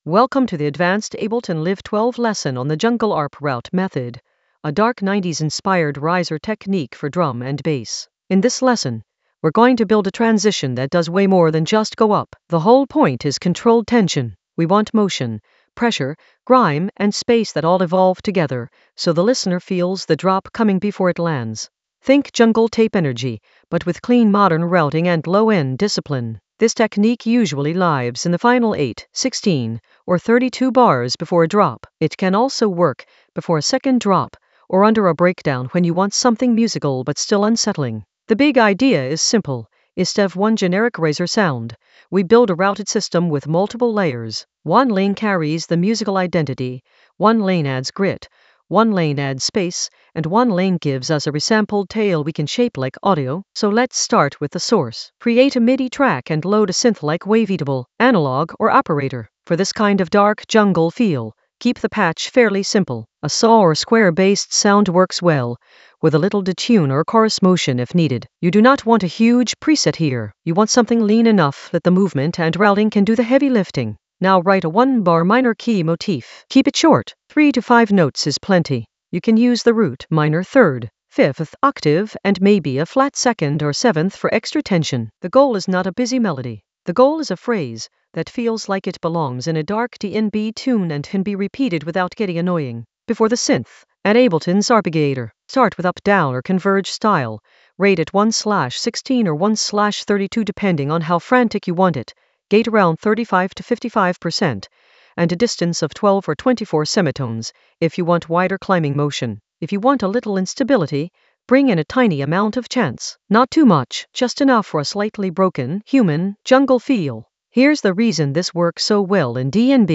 Narrated lesson audio
The voice track includes the tutorial plus extra teacher commentary.
An AI-generated advanced Ableton lesson focused on Jungle arp route method for 90s-inspired darkness in Ableton Live 12 in the Risers area of drum and bass production.